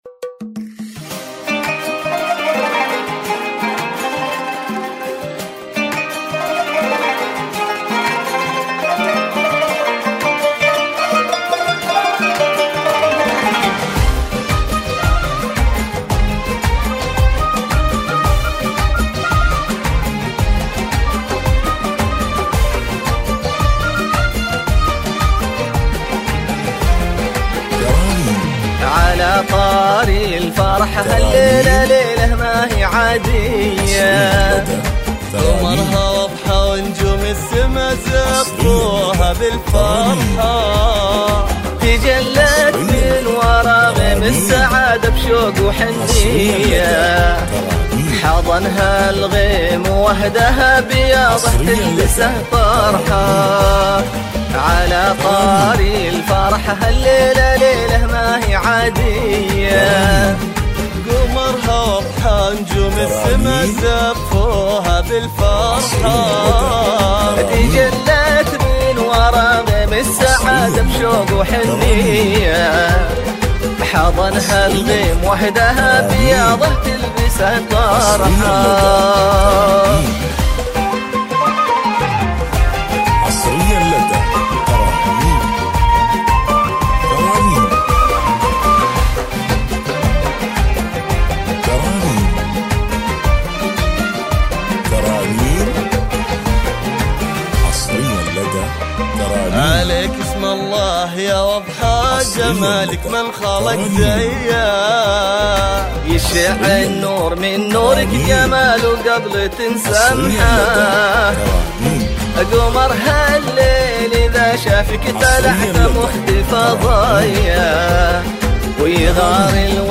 زفه عروس